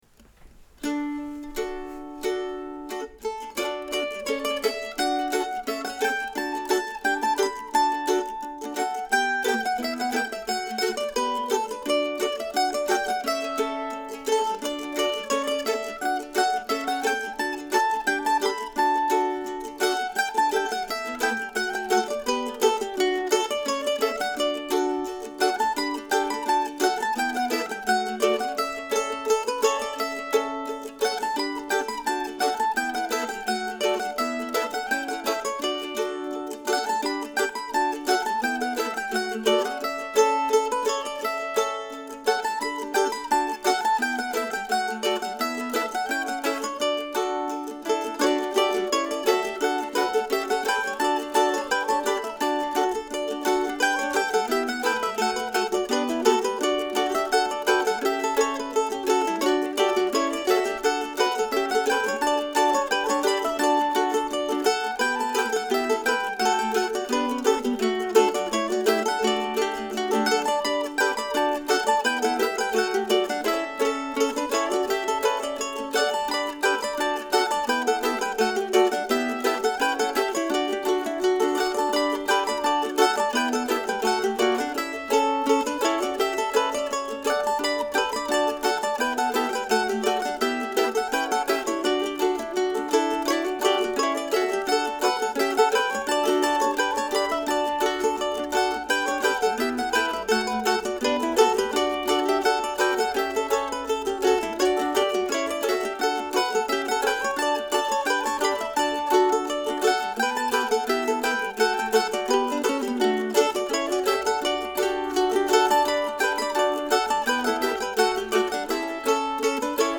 I recorded it at what I thought was a nice march tempo as a mandolin trio with a fun harmony part and the 3rd mando playing chords.